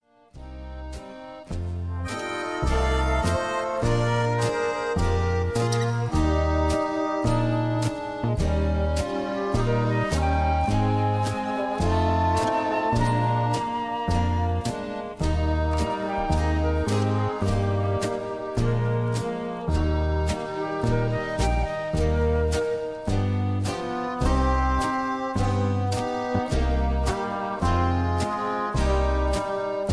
(Key-Eb)
Tags: backing tracks , irish songs , karaoke , sound tracks